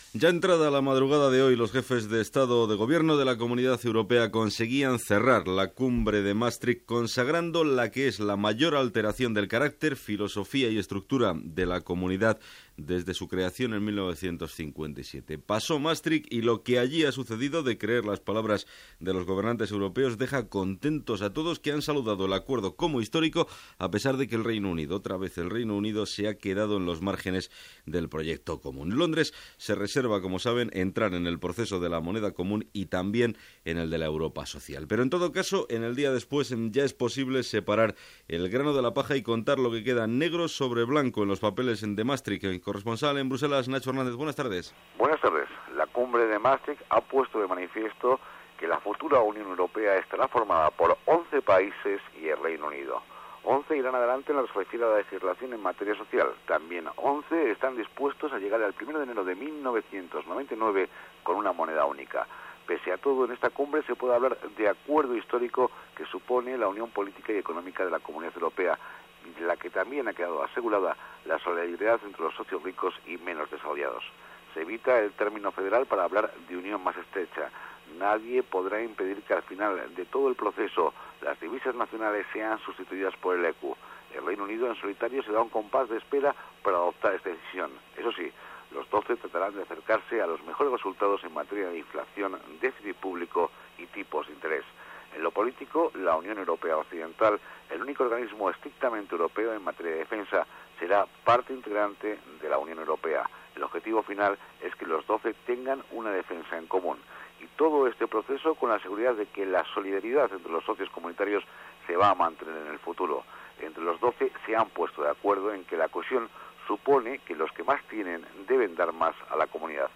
S'ha arribat a un acord en les negociacions de la cimera del Tractat de Maastricht per constituir la Unió Europea. Informació des de Busel·les. Declaracions del president del govern espanyol Felipe González
Informatiu